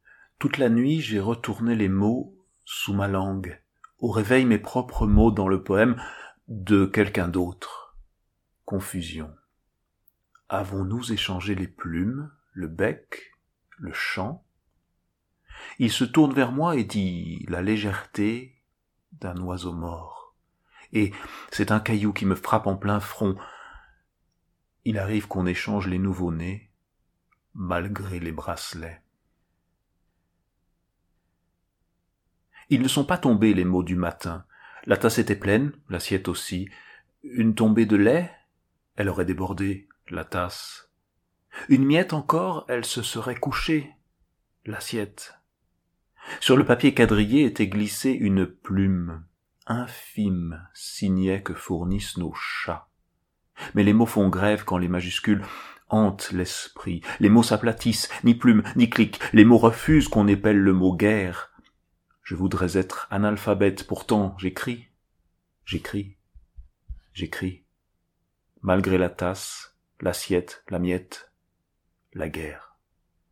Deux poèmes